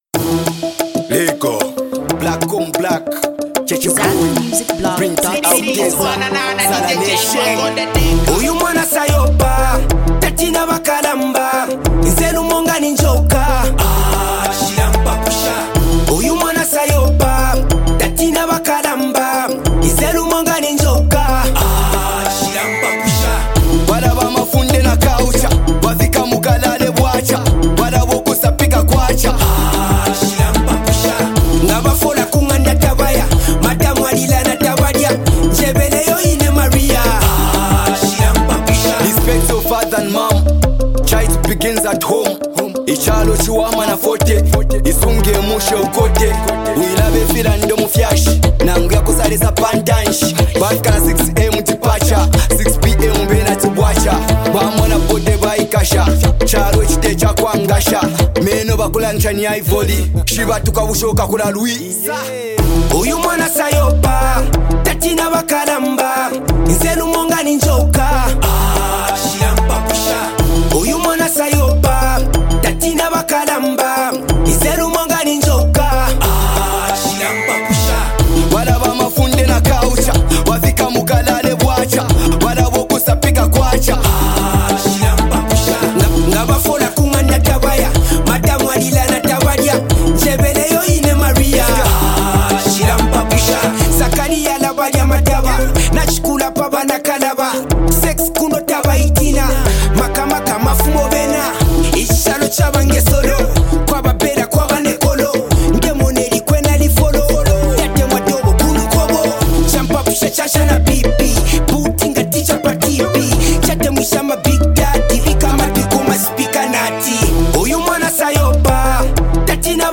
street banger